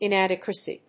音節in・ad・e・qua・cy 発音記号・読み方
/ìnˈædɪkwəsi(米国英語), ˌɪˈnædɪkwʌsi:(英国英語)/